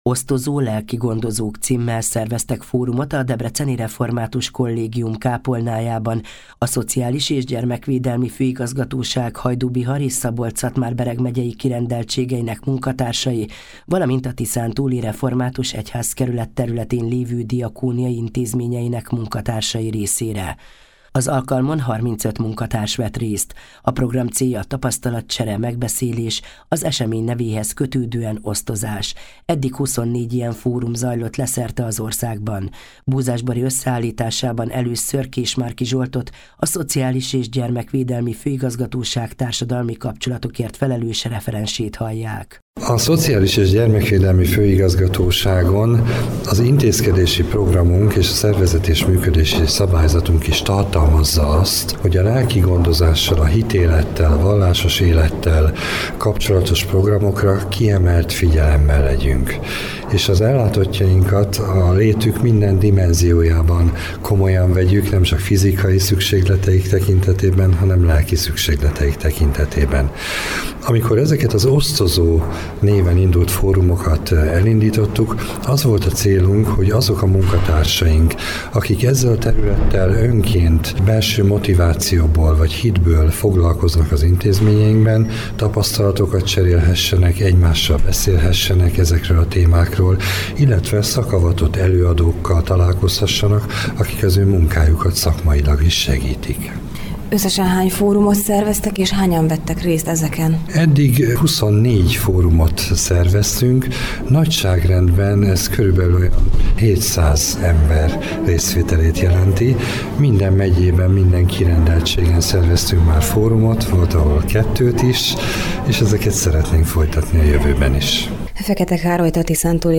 készített összeállítást az Európa Rádió ban